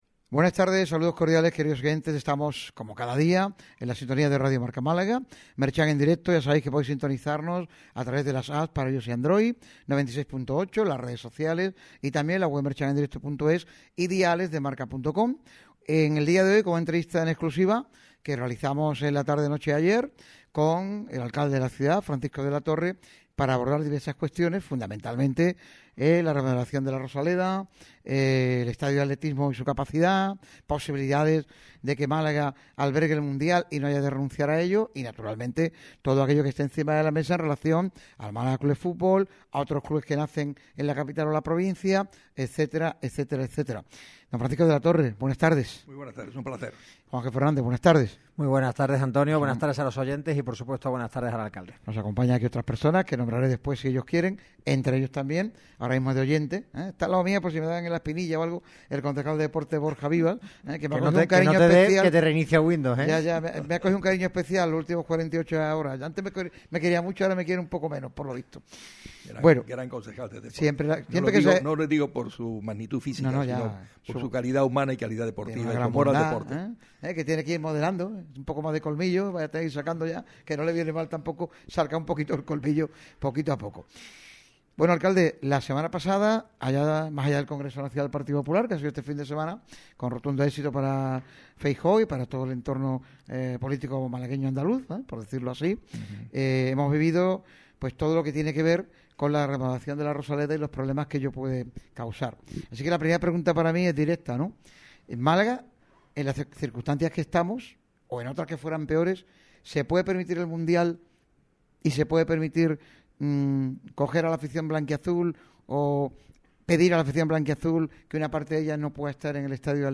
Francisco de la Torre atiende a Radio MARCA Málaga en una entrevista en exclusiva en la que se abordaron los temas claves del fútbol.